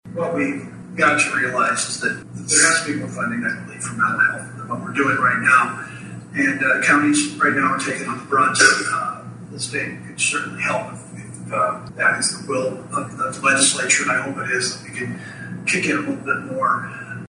The first Carroll Chamber of Commerce Legislative Forum on Saturday was filled with questions revolving around funding.